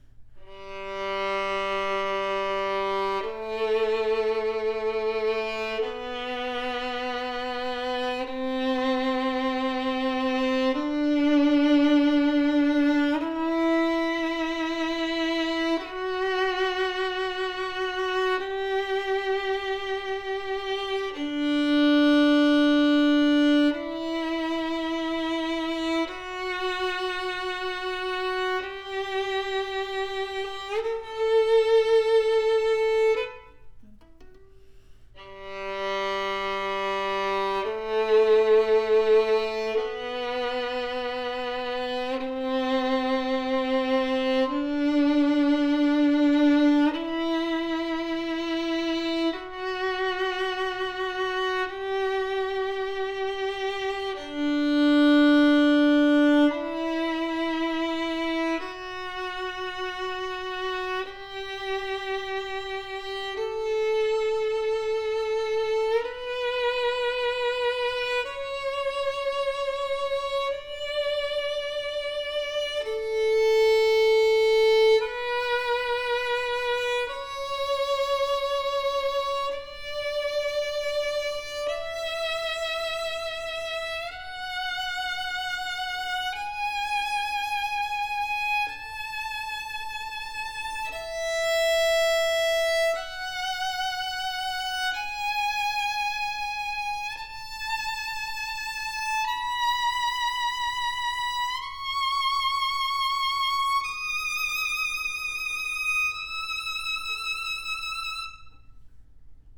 Compact playability, punchy deep tone.
Audio CliP ( Scale ):
Sonorous, complex and deep tone. Having the “butter” sound as one would expect from an elite level instrument. Made after the 1744 “Ole Bull” Guarneri del Gesu, 25-30 yrs old Italian spruce and Bosnian maple, going through same wood treatment as our competition level violins. 353 mm body length that delivers super playability without being too small, punchy powerful projection with clear and rich tone color.